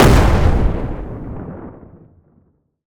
explosion_large_06.wav